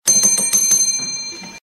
RECEPTION BELL RINGING.mp3
Recorded in a hotel reception, pretty small, trying to get someone for assistance.
reception_bell_ringing_0bw.ogg